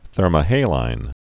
(thûrmə-hālīn, -hălīn)